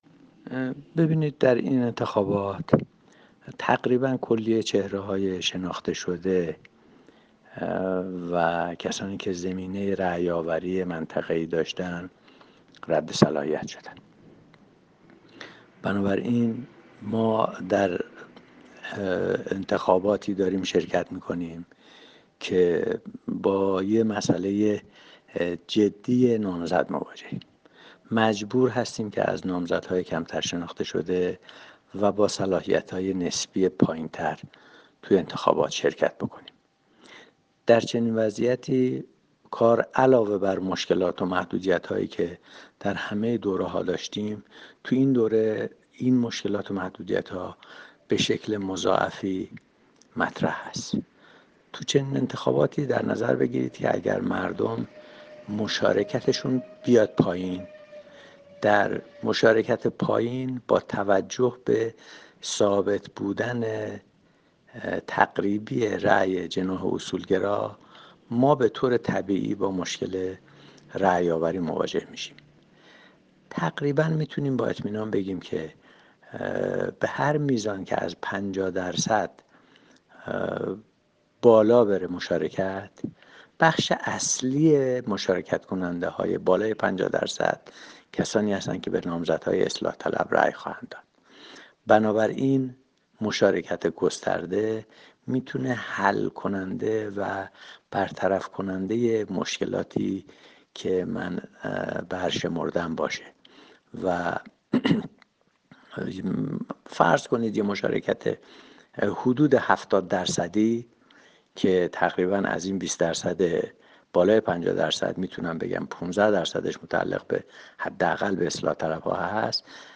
گفت و گوی صوتی با فیض‌الله عرب‌سرخی درباره انتخابات مجلس دهم